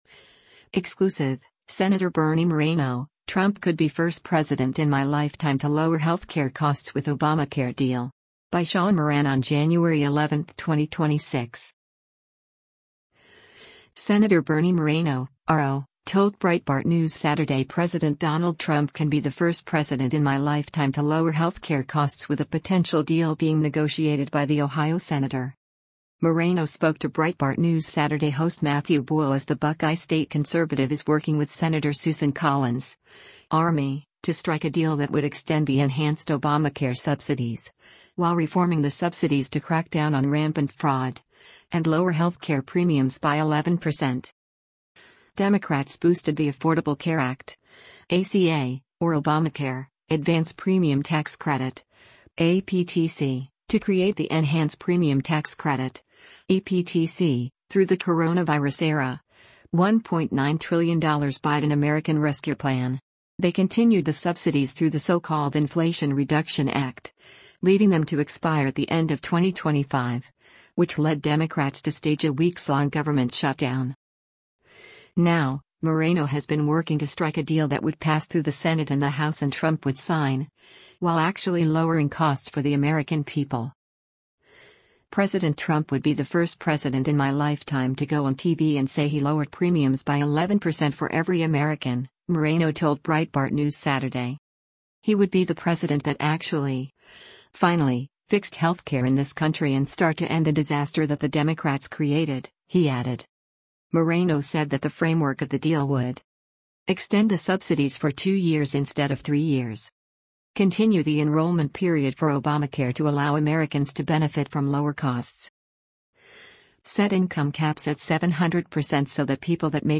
Sen. Bernie Moreno (R-OH) told Breitbart News Saturday President Donald Trump can be the “first president in my lifetime” to lower healthcare costs with a potential deal being negotiated by the Ohio senator.